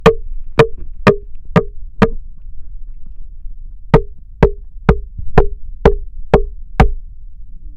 두드리는01.mp3